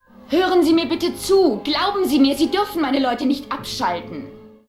(TAS: Phantasie oder Wirklichkeit / Urlaub im Wunderland, ZDF-Synchronfassung)
Uhura_fleht_den_Gedankenduplikator_an_ihre_Leute_nicht_abzuschalten.ogg